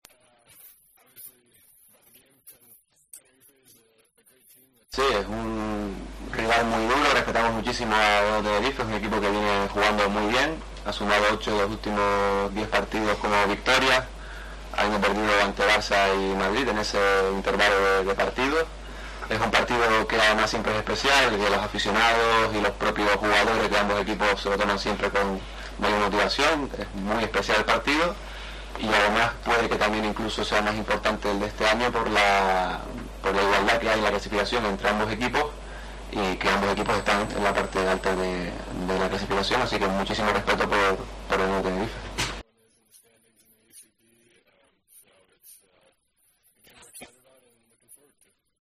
El jugador habló ante los medios en la previa del derbi ante el Lenovo Tenerife